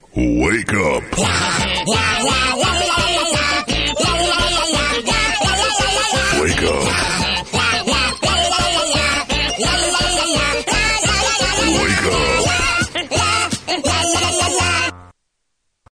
Kategorien: Wecktöne